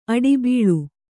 ♪ aḍbīḷu